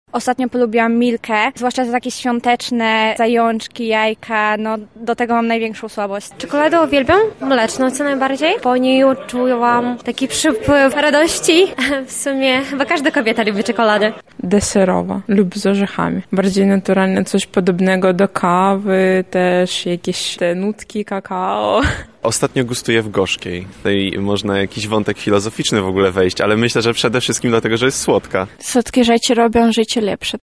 [SONDA] Czy ktoś w Lublinie nie lubi czekolady?
O to jaki rodzaj rodzaj tego przysmaku lublinianie lubią najbardziej nasza reporterka zapytała mieszkańców naszego miasta?